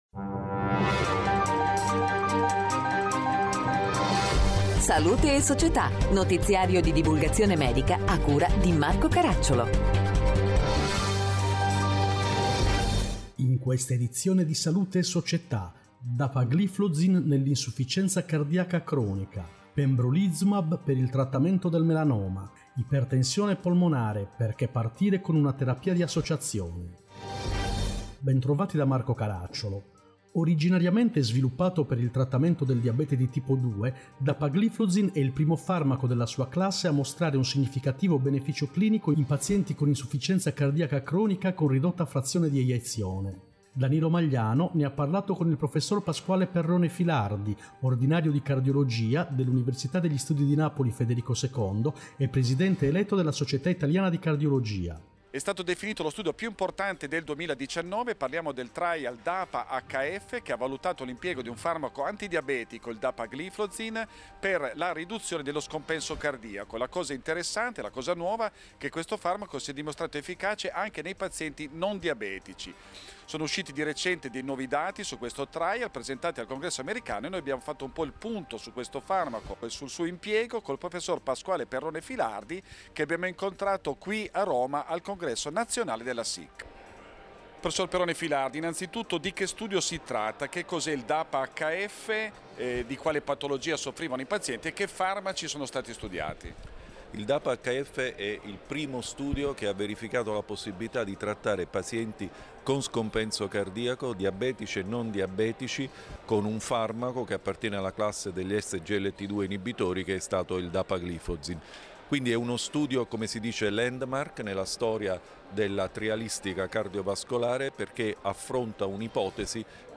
In questa edizione: Dapagliflozin nell’insufficienza cardiaca cronica Pembrolizumab per il trattamento del melanoma Ipertensione polmonare, Perchè partire con una terapia di associazione Interviste